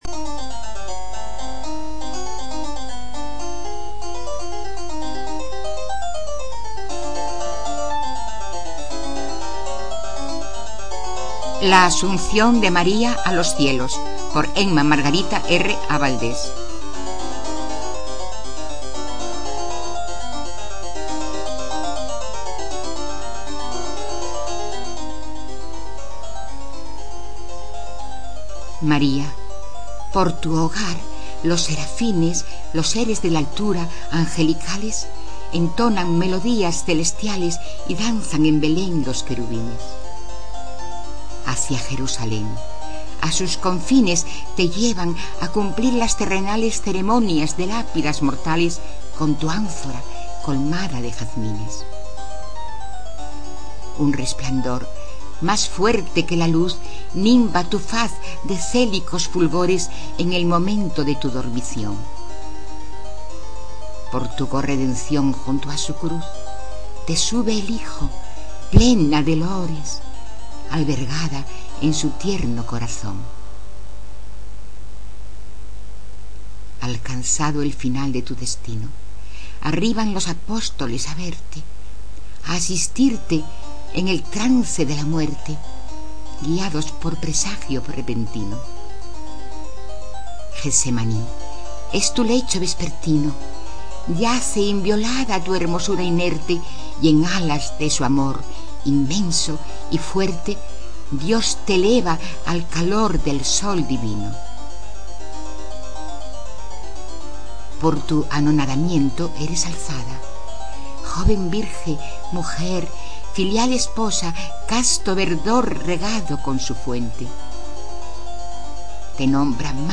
Poesías